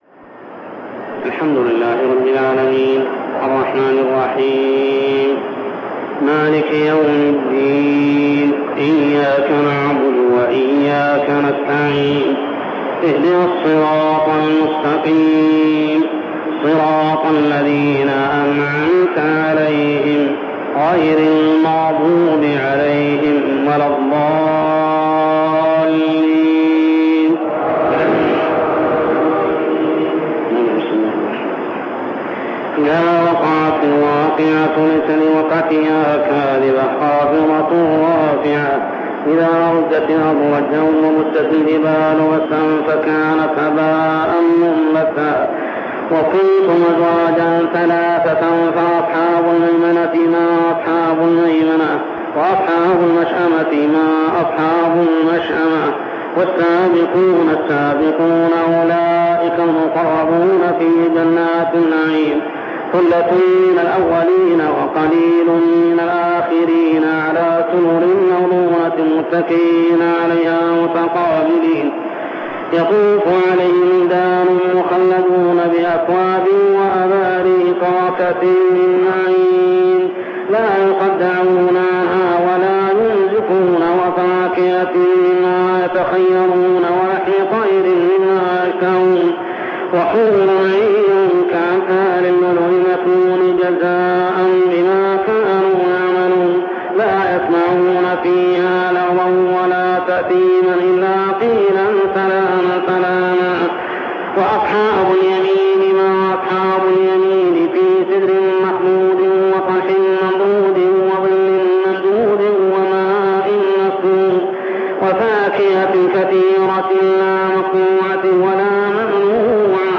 تسجيلات تراويح الحرم المكي 1402 هـ